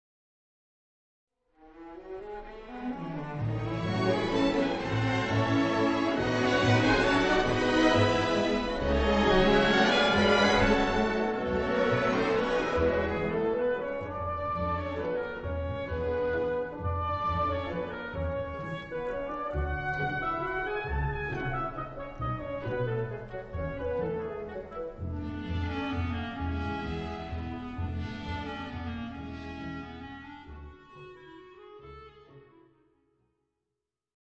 April 2003 Concert
glowing strings